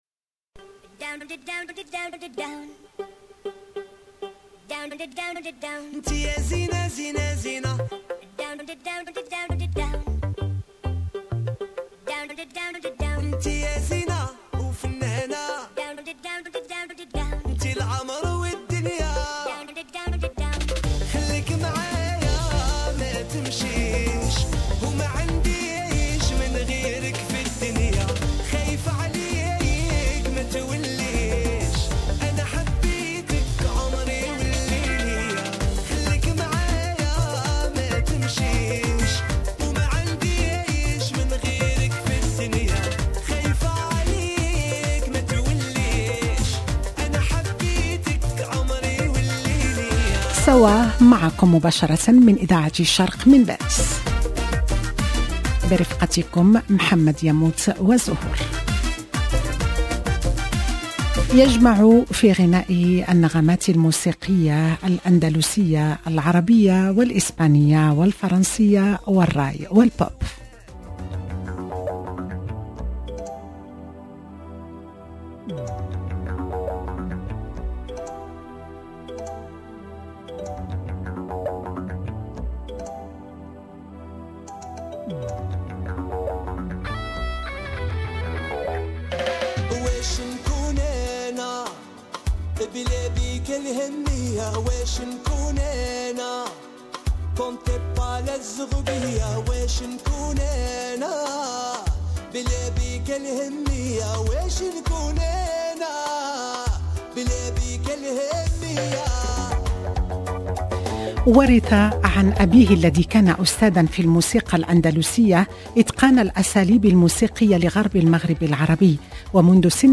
Considéré aujourd'hui comme un des grands artistes marocains, Hamid BOUCHNAK est doué pour le chant et la composition, il ne manque pas de nous épater par son exceptionnel timbre de voix et une interprétation hors du commun. 0:00 23 min 24 sec